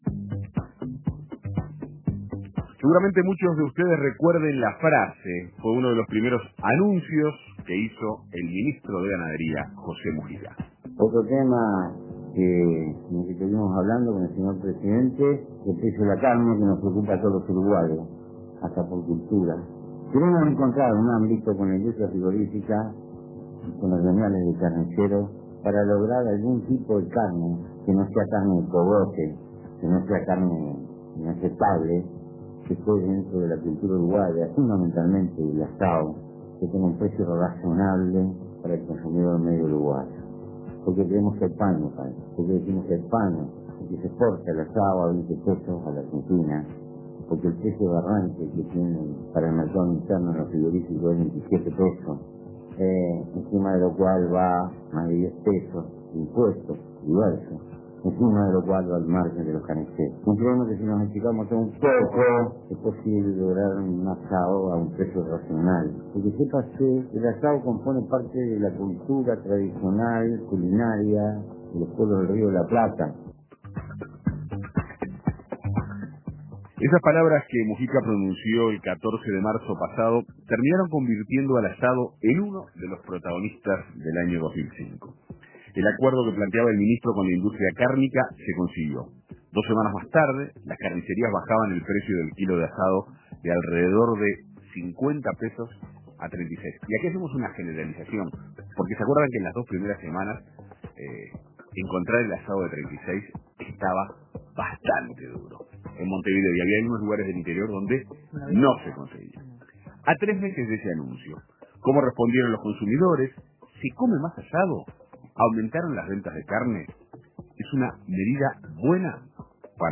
Informes A tres meses del anuncio del asado del "Pepe", carniceros, nutricionistas y amas de casa comparten sus puntos de vista sobre el tema.